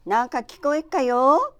Aizu Dialect Database
Type: Yes/no question
Final intonation: Rising
Location: Aizuwakamatsu/会津若松市
Sex: Female